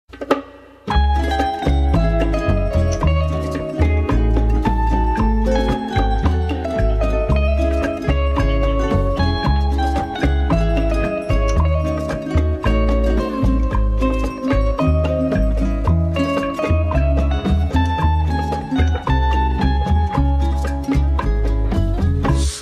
Categoría Pop